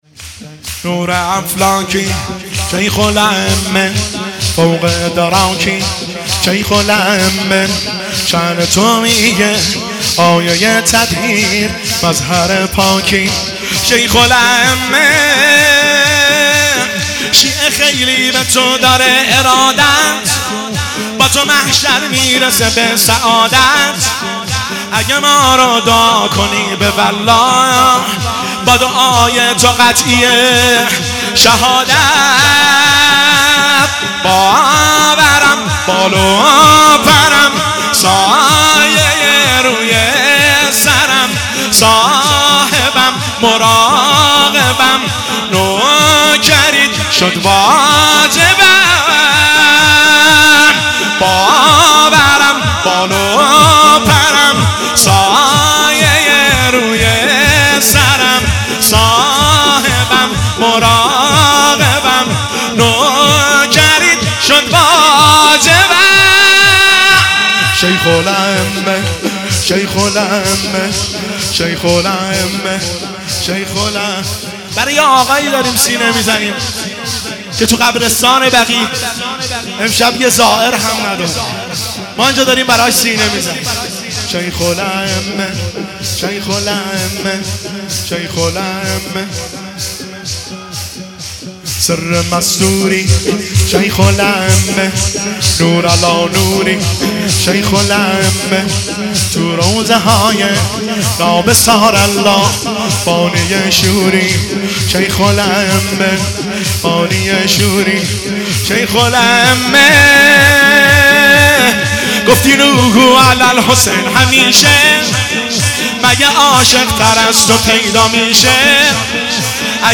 مداحی شور